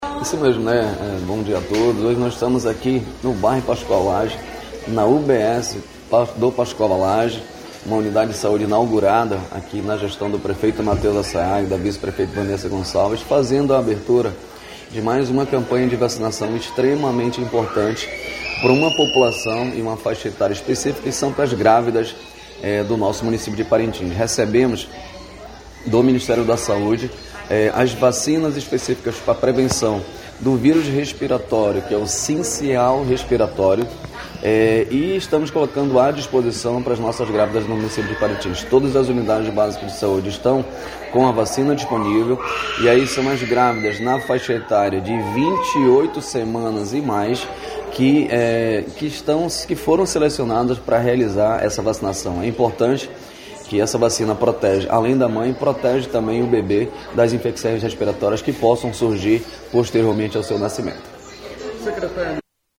Para o secretário municipal de Saúde, Clerton Florêncio, a adesão das gestantes é fundamental para reduzir riscos logo após o nascimento.
Sonora-1-Clerton-Florencio.mp3